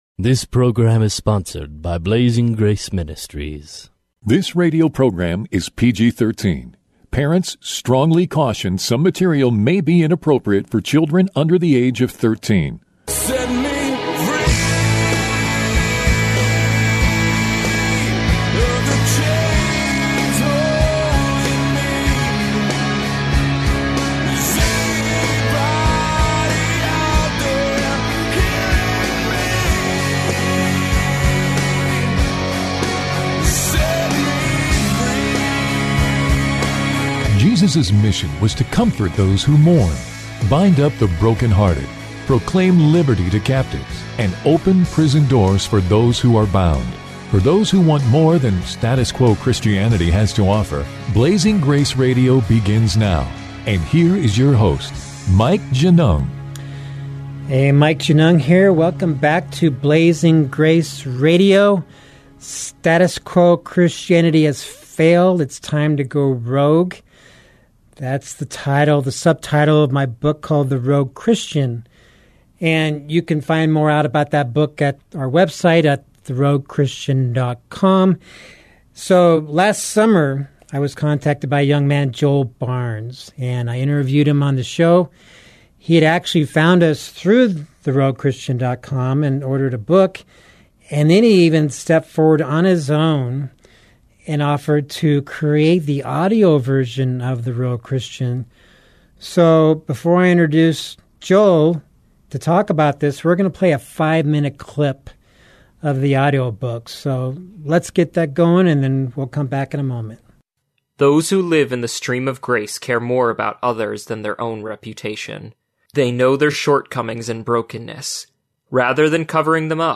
A 5 minute clip is played at the beginning of the show.